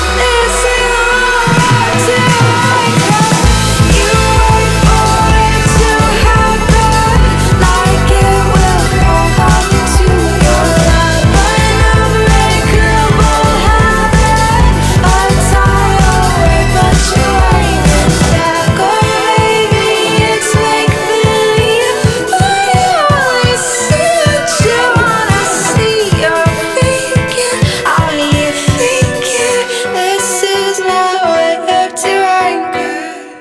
поп
красивые
женский вокал
dance
Electronic